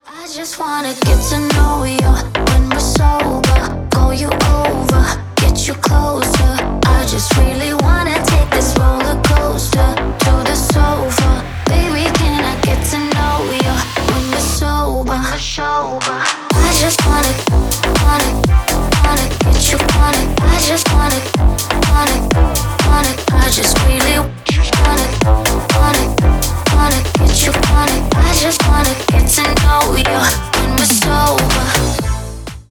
Ремикс # Танцевальные